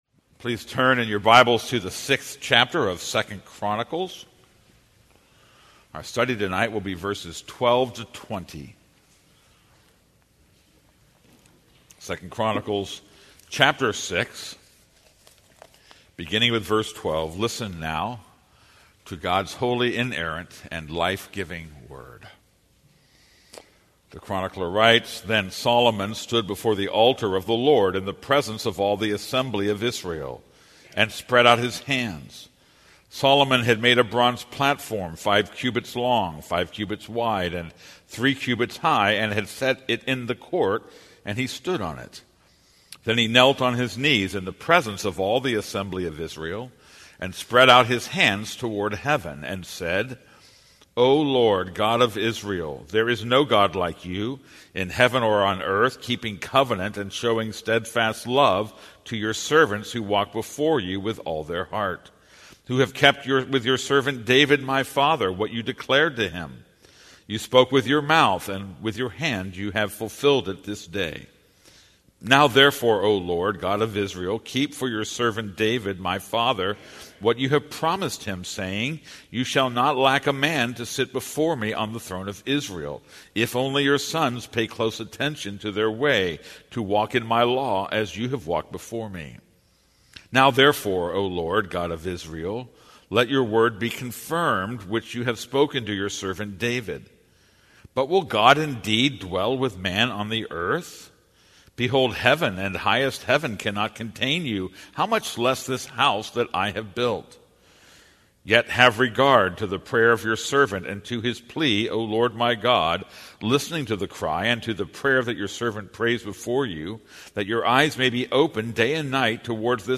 This is a sermon on 2 Chronicles 6:12-20.